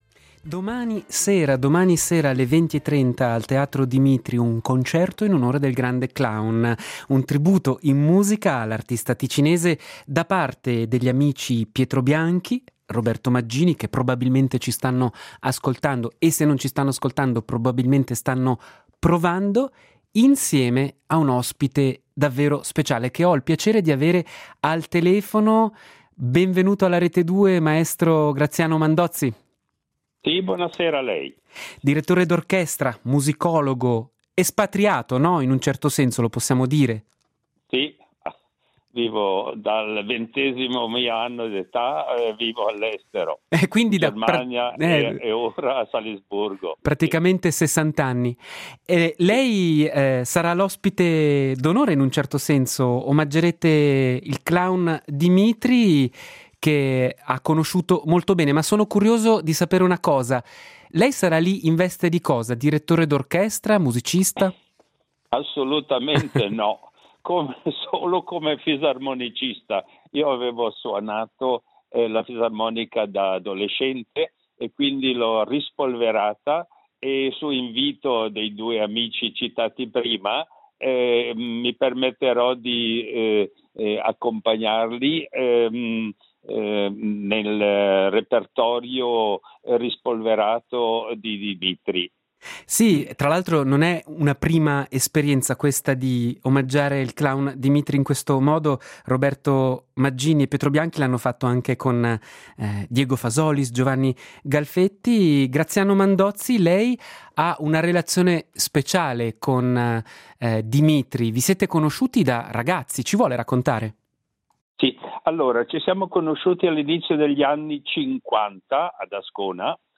Omaggio musicale